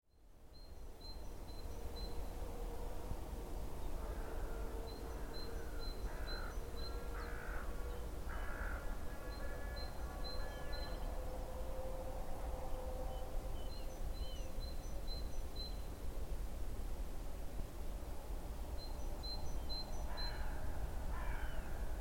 Зимний лесной звук в солнечный день